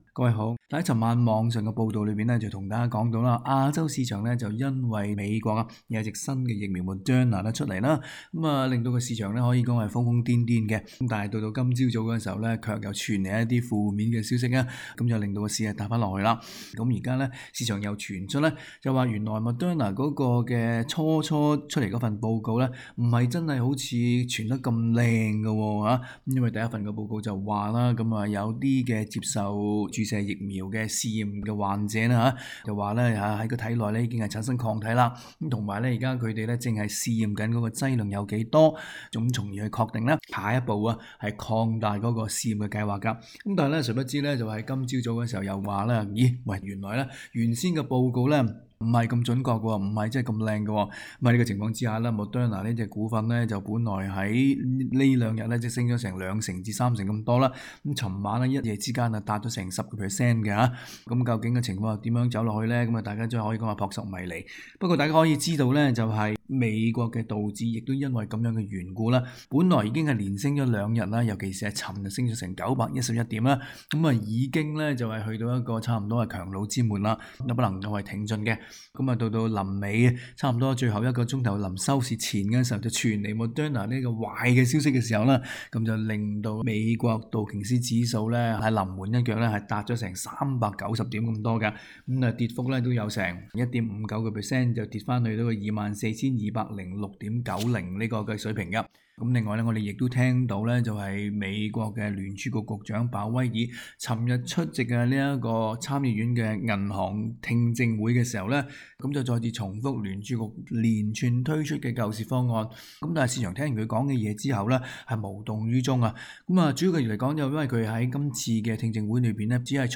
詳情請收聽今天的訪問內容。